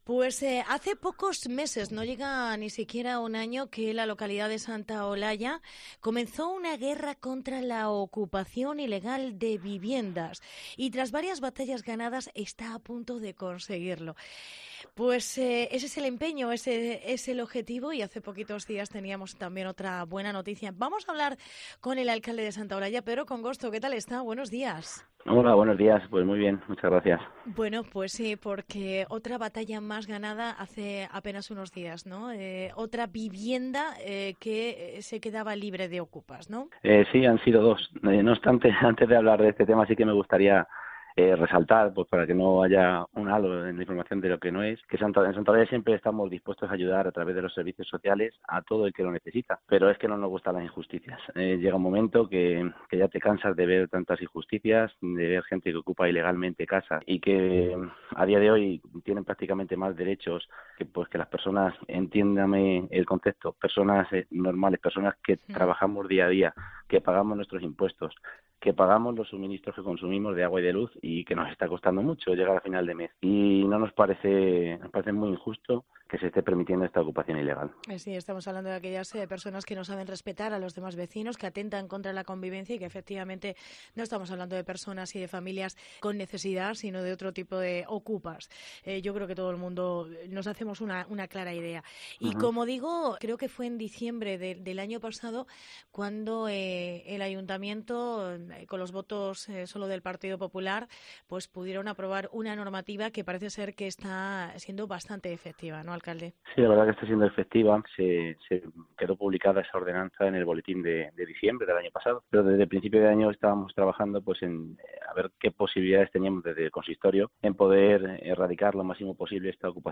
Entrevista Pedro Congosto, alcalde de Santa Olalla